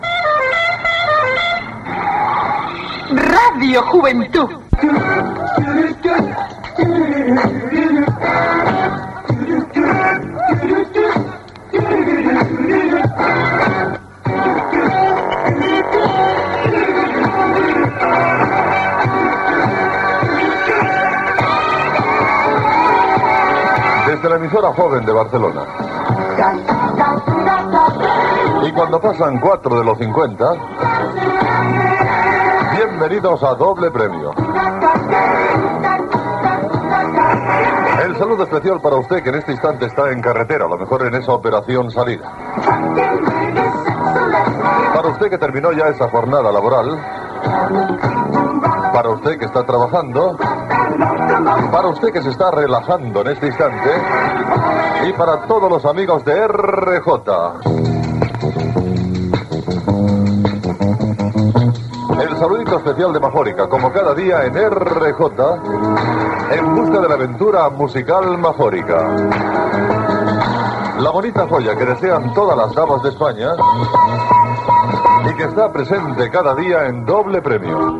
Indicatiu de l'emissora, hora, presentació del programa i publicitat.
Musical